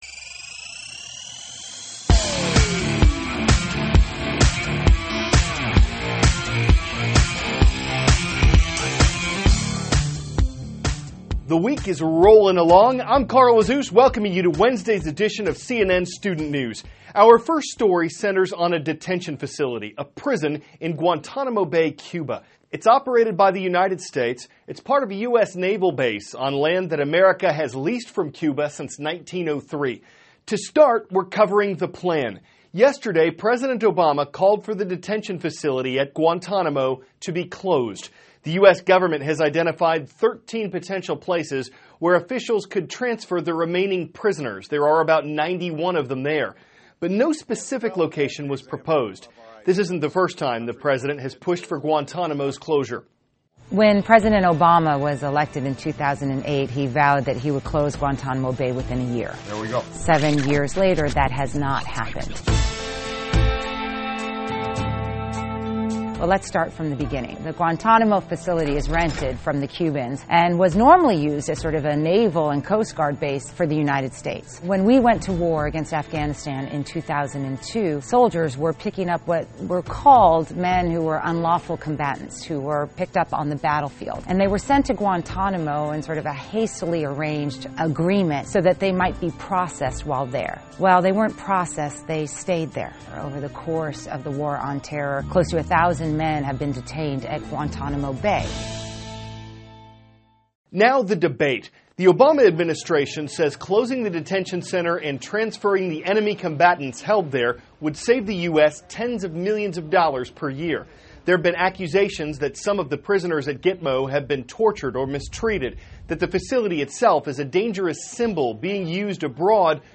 (CNN Student News) -- February 24, 2016 Obama Gives Congress Guantanamo Closure Plan; Water Crisis Impacting New Delhi. Aired 4-4:10a ET THIS IS A RUSH TRANSCRIPT.